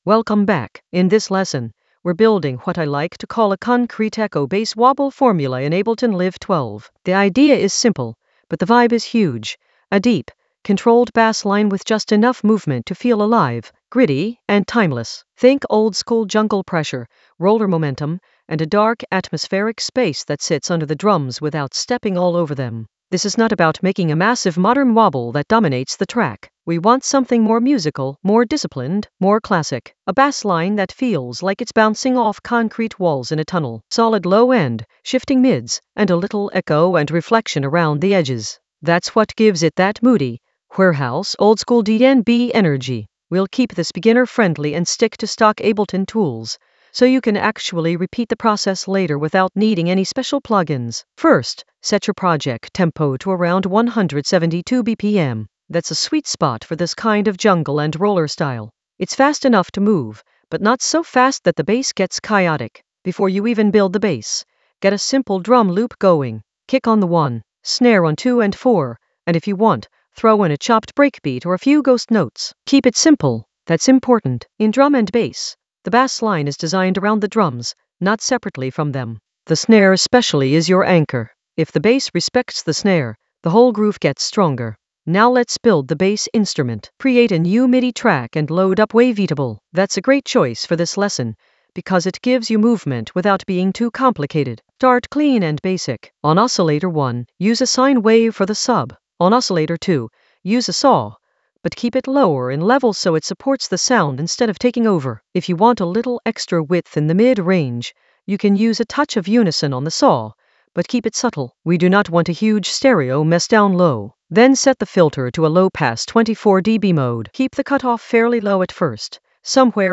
Narrated lesson audio
The voice track includes the tutorial plus extra teacher commentary.
An AI-generated beginner Ableton lesson focused on Concrete Echo Ableton Live 12 bass wobble formula for timeless roller momentum for jungle oldskool DnB vibes in the Atmospheres area of drum and bass production.